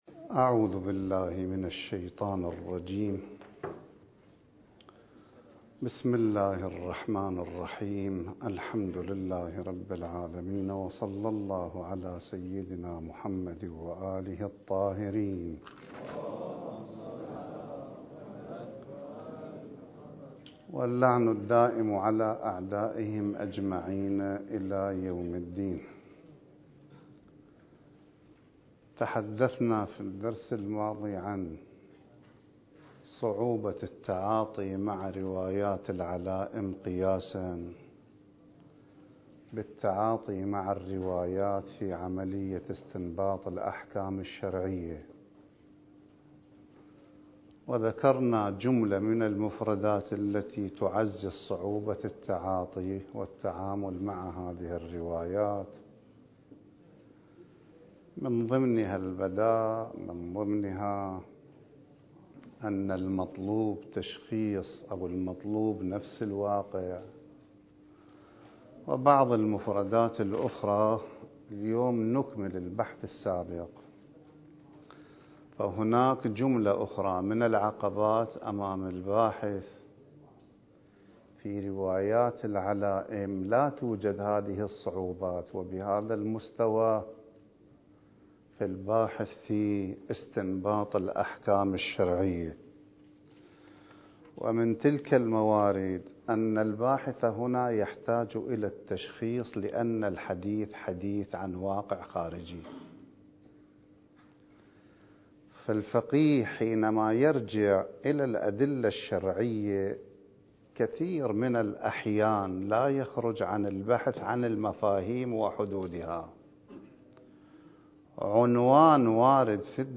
الدورة المهدوية الأولى المكثفة (المحاضرة السادسة)